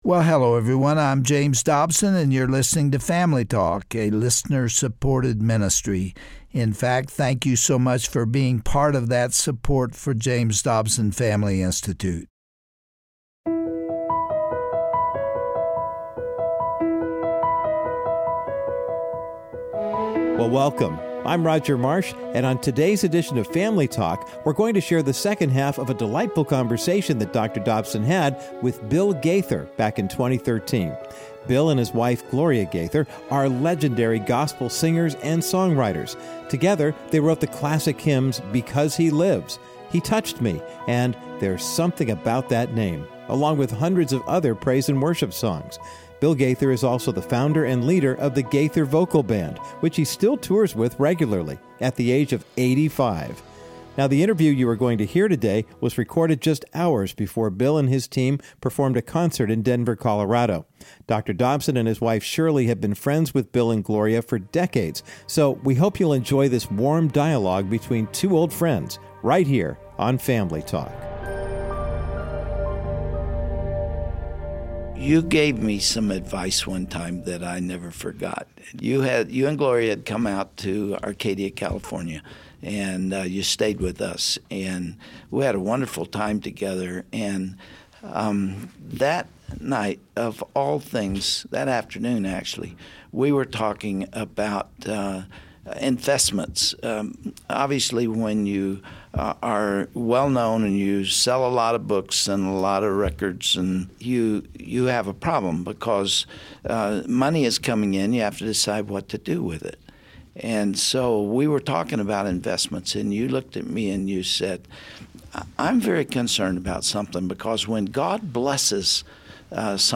However, on today's edition of Family Talk, this former English teacher reveals to Dr. James Dobson that his passion is to teach the next generation some key lessons.